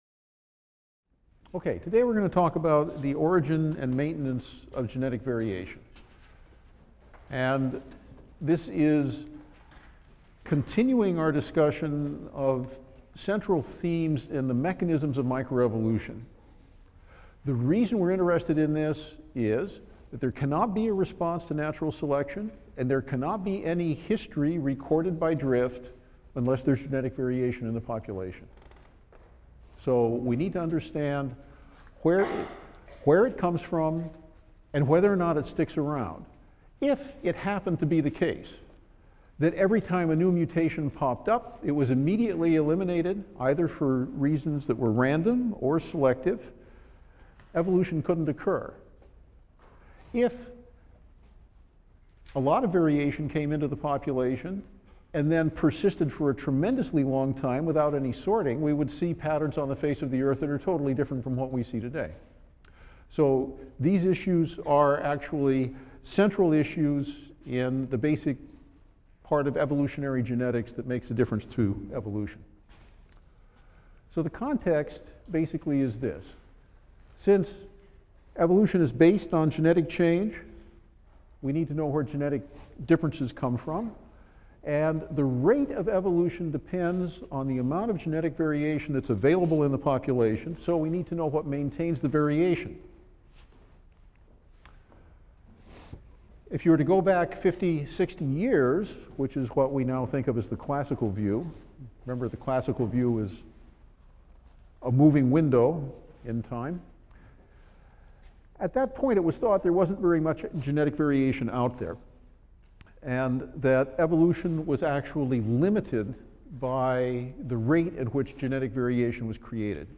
E&EB 122 - Lecture 6 - The Origin and Maintenance of Genetic Variation | Open Yale Courses